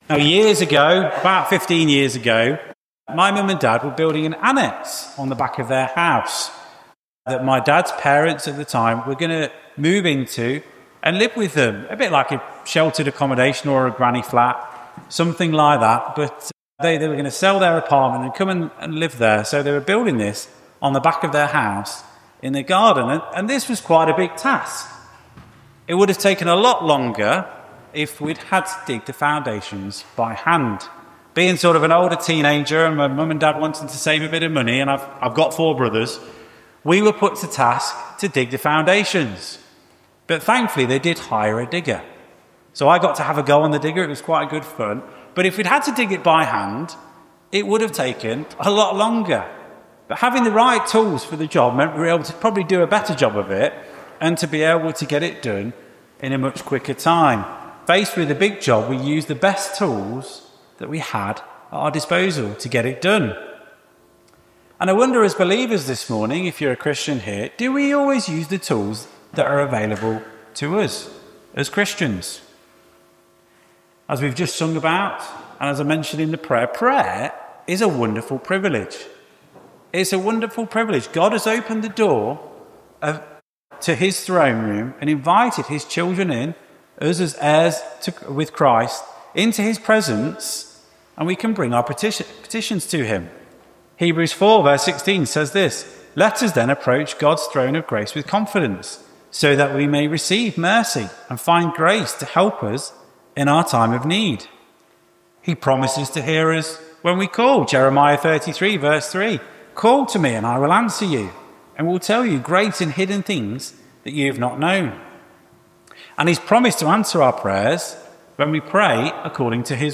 Sermons: Ashbourne Baptist Church 2025 | (Hope For Ashbourne YouTube channel)